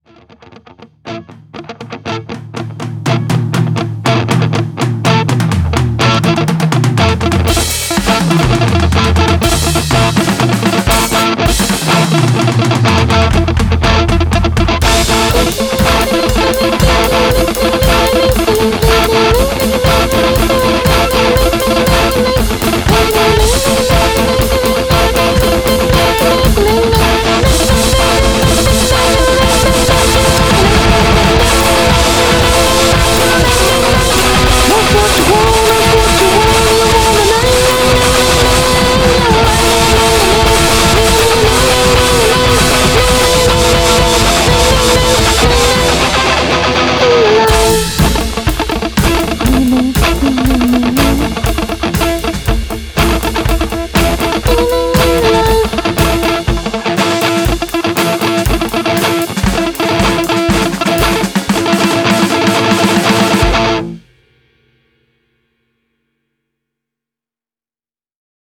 Ambiant Rock
Main Vocals
Drums
Electric Guitar